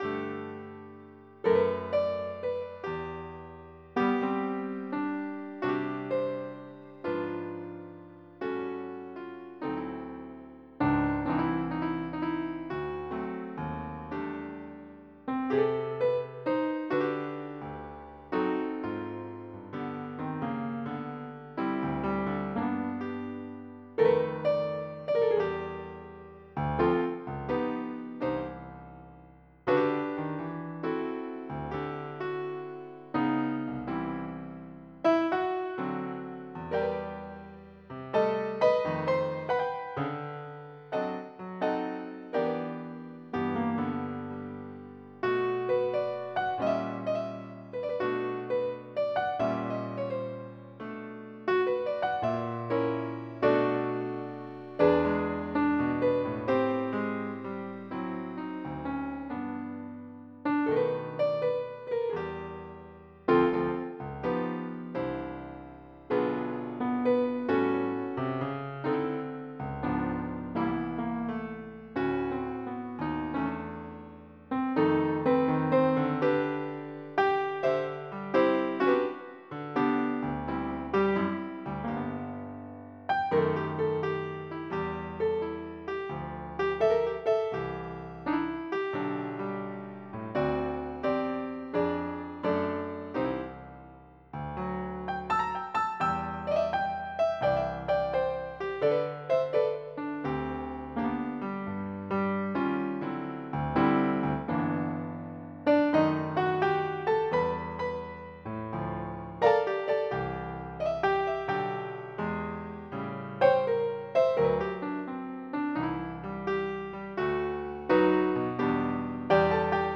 Jazz / PLASIR.MID
MIDI Music File